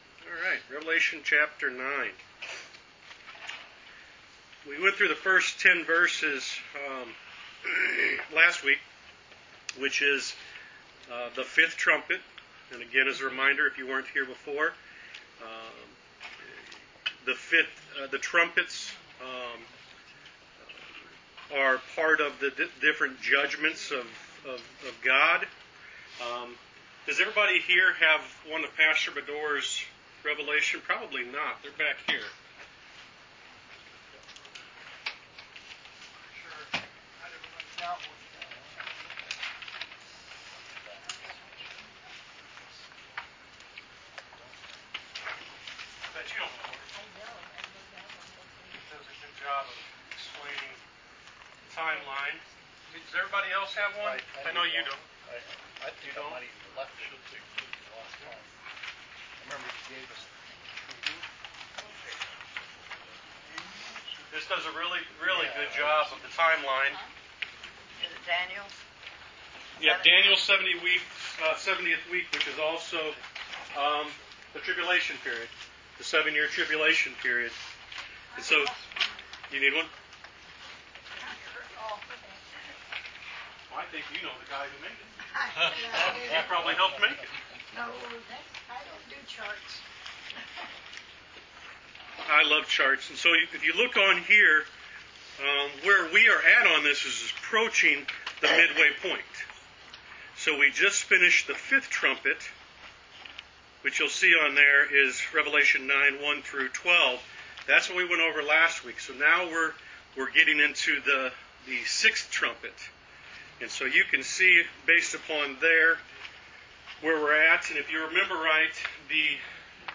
Wednesday Bible Study: Rev Ch 9 Pt 2